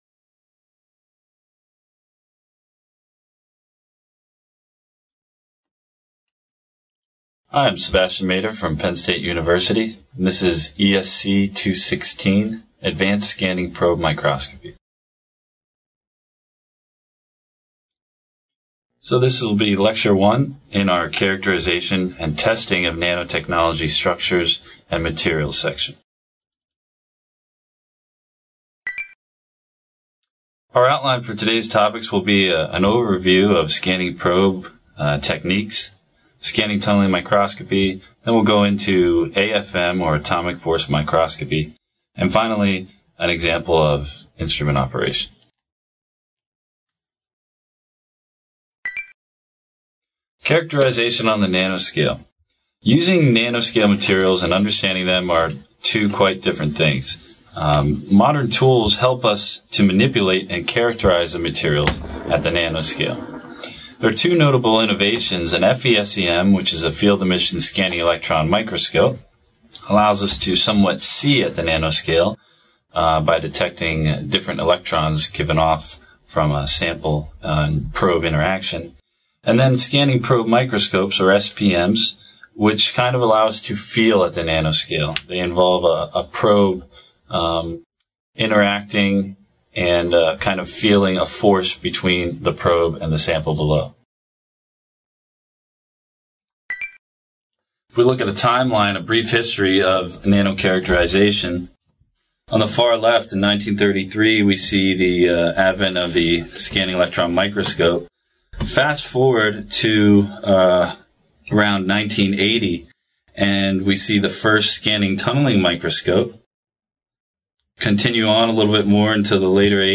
This video, published by the Nanotechnology Applications and Career Knowledge Support (NACK) Center at Pennsylvania State University, is part one of a two-part lecture on advanced scanning probe microscopy for characterization and testing of nanostructures.